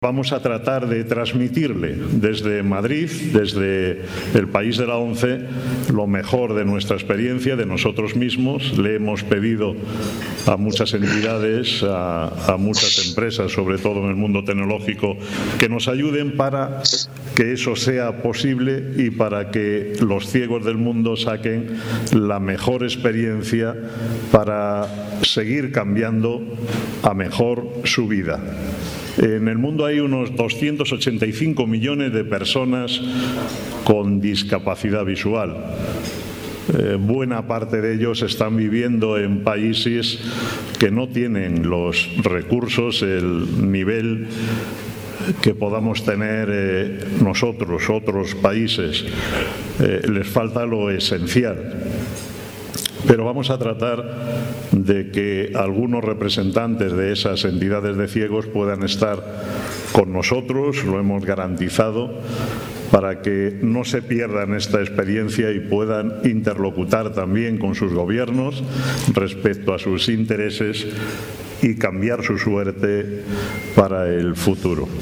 en el transcurso de un desayuno informativo organizado por Nueva Economía Forum